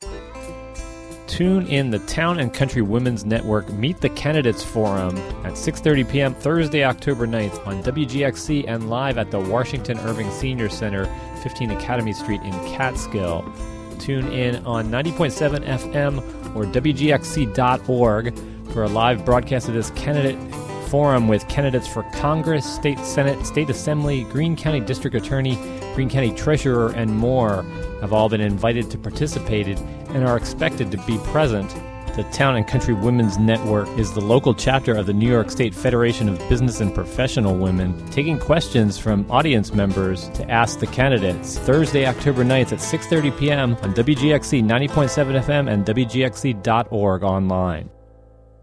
An Official promo for Town and Country Women's Network Meet the Candidates Forum broadcast Oct. 9 on WGXC. (Audio)